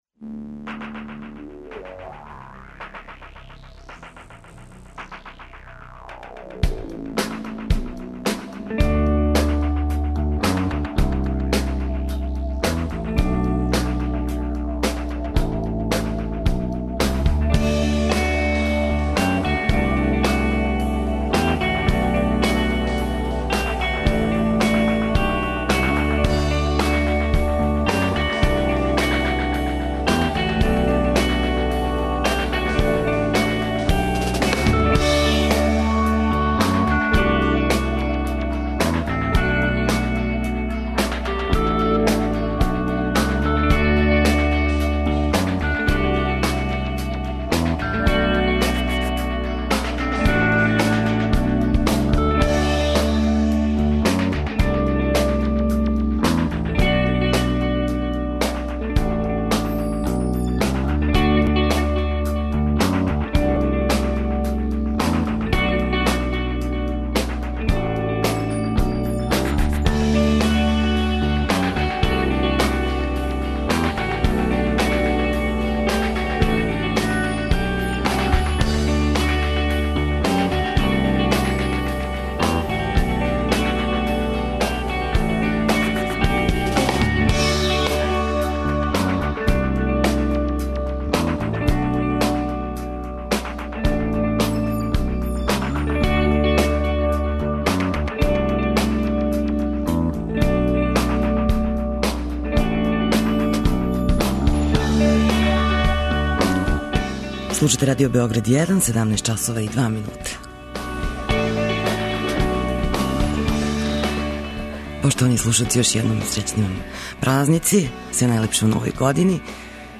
Гости су чланови "Синестезије" једног од најперспективнијих састава. Повод за гостовање је њихов први албум који су издали последњих дана 2011. године.